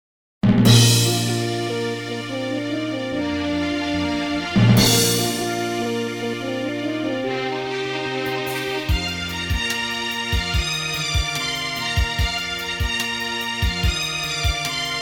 News/Morning Announcements